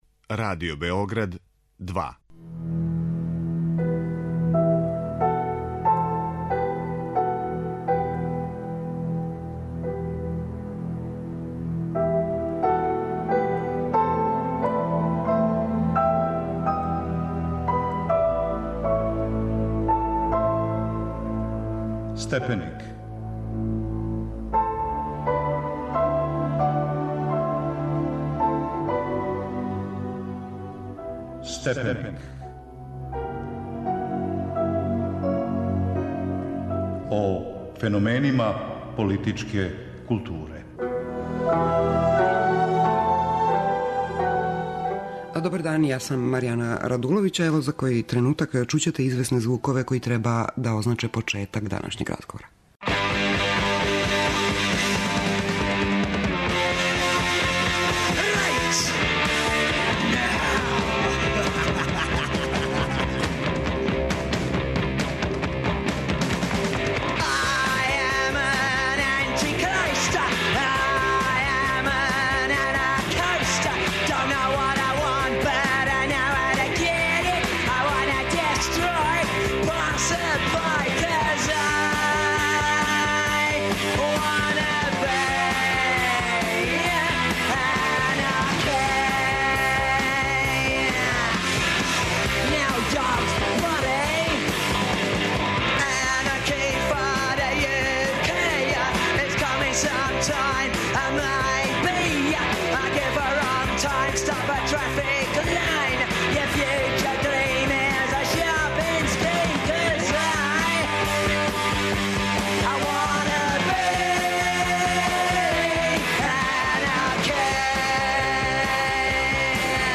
О књизи 'Трагови кармина - тајна историја XX века', Грејла Маркуса, разговарамо